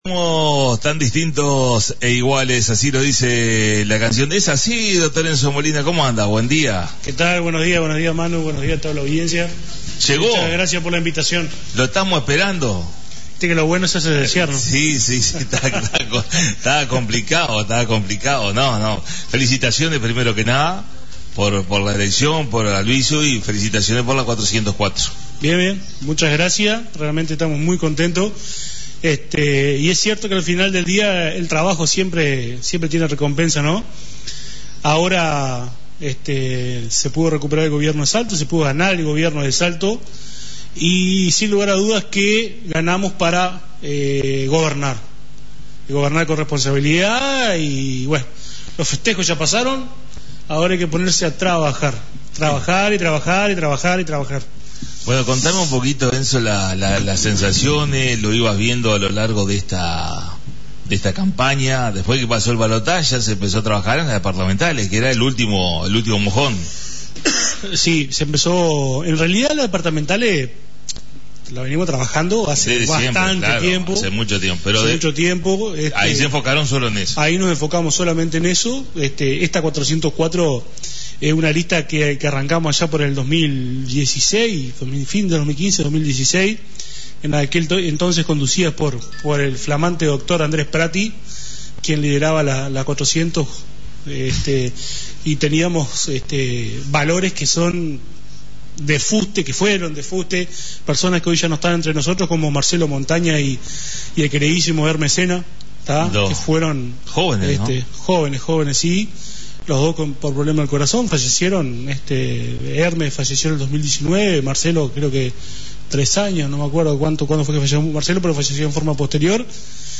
Nos visitó el Edil Dr Enzo Molina de la 404 la Lista mas votada del Departamento.